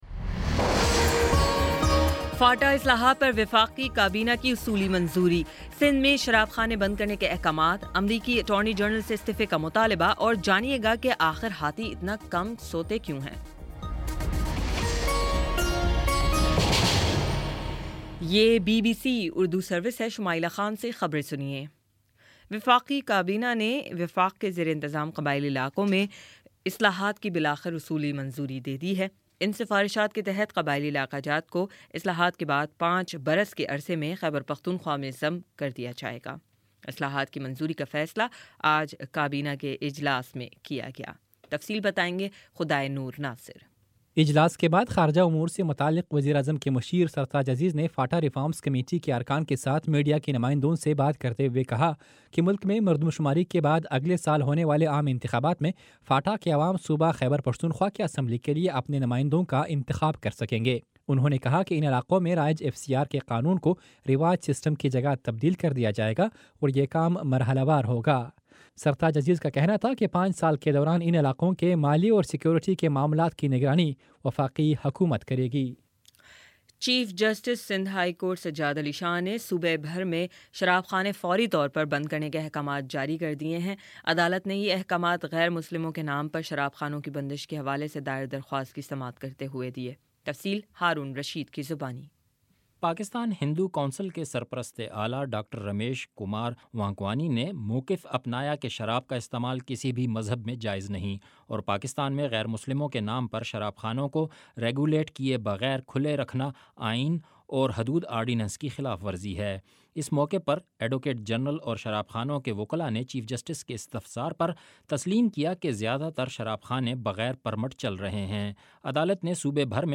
مارچ 02 : شام چھ بجے کا نیوز بُلیٹن